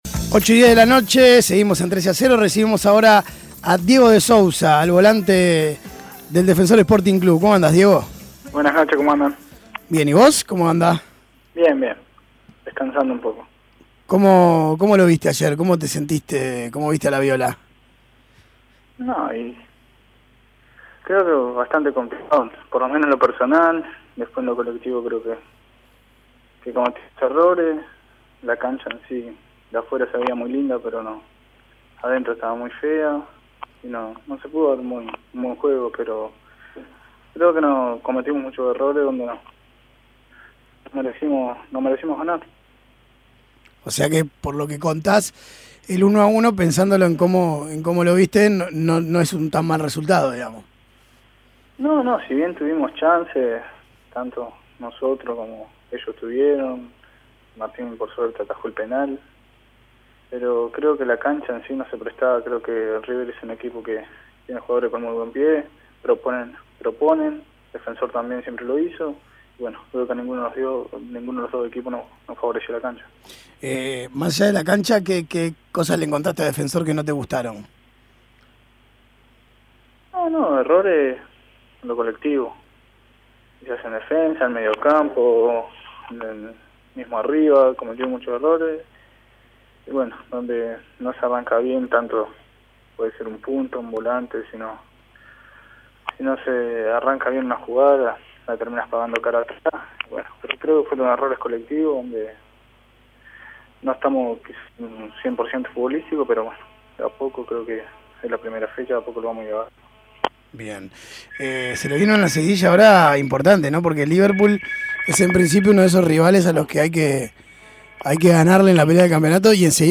Testimonios